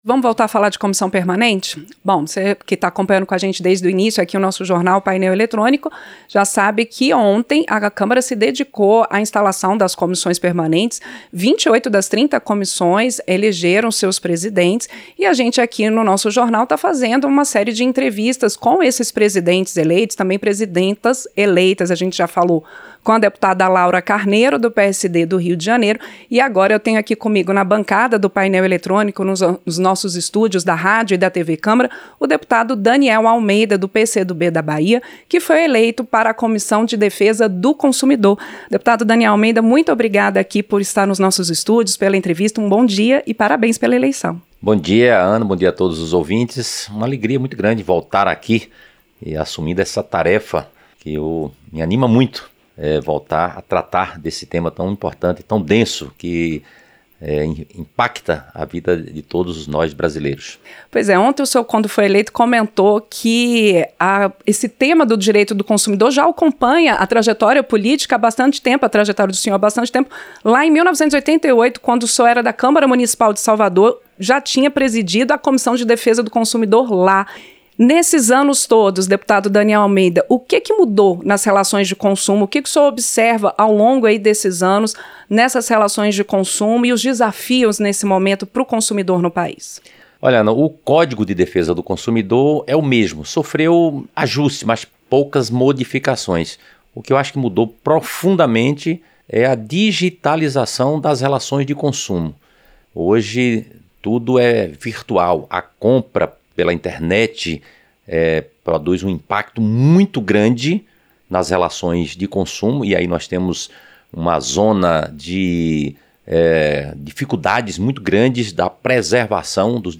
Entrevista - Dep. Daniel Almeida (PCdoB-BA)